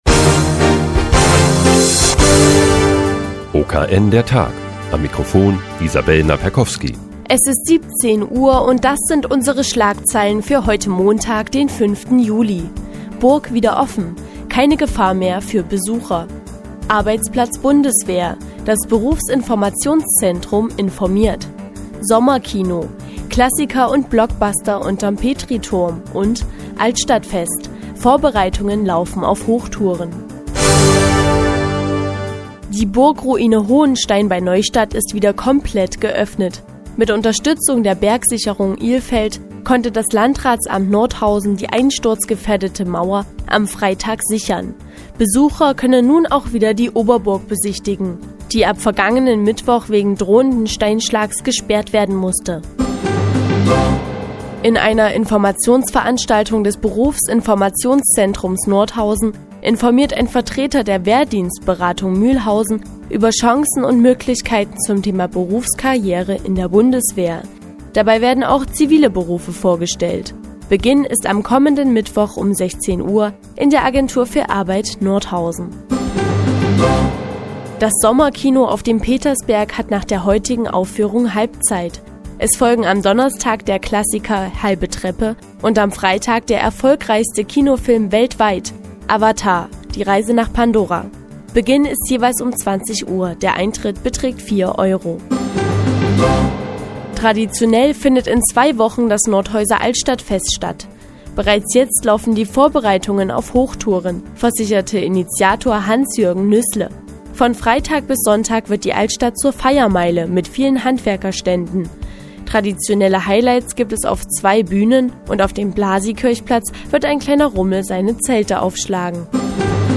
Die tägliche Nachrichtensendung des OKN ist nun auch in der nnz zu hören. Heute geht es um das Nordhäuser Altstadtfest und Sommerkino auf dem Petersberg.